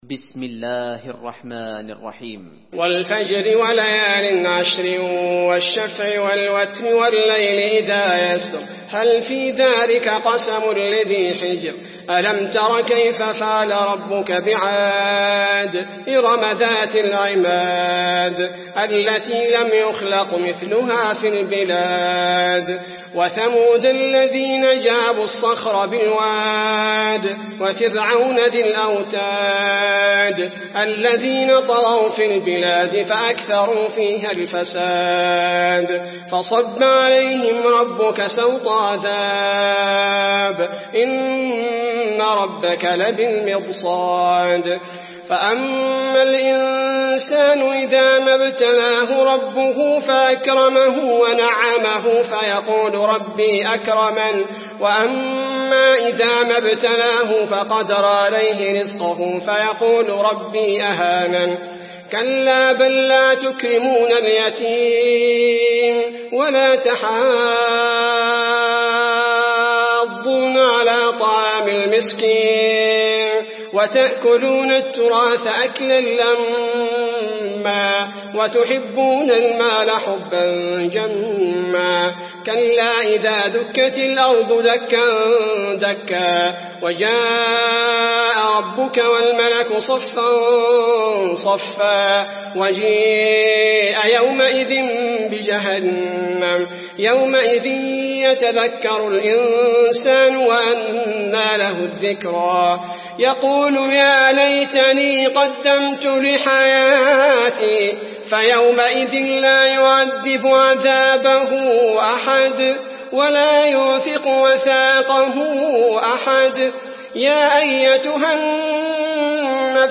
المكان: المسجد النبوي الفجر